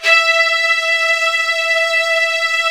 55u-va11-E4.aif